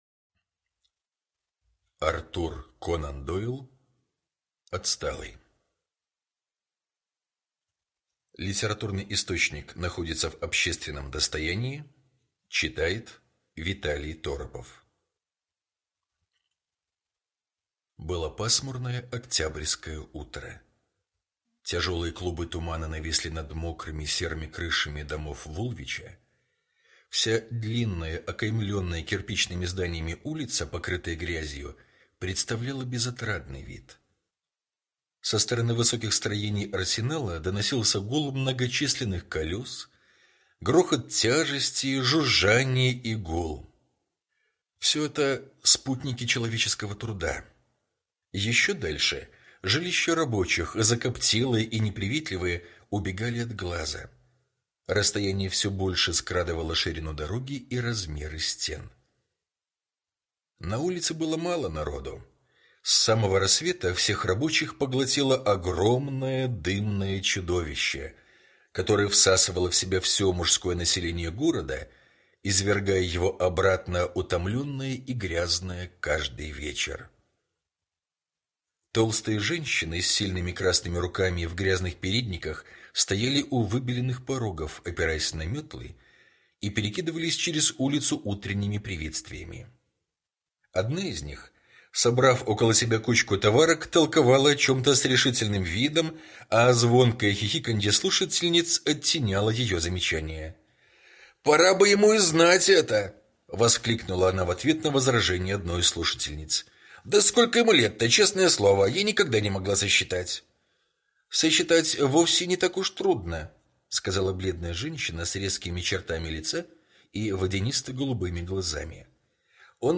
Отсталый — слушать аудиосказку Артур Конан Дойл бесплатно онлайн